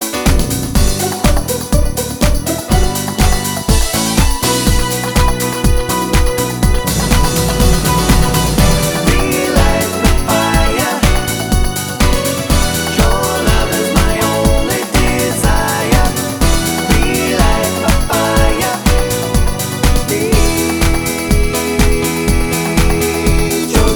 Male Solo Version Pop (1990s) 3:58 Buy £1.50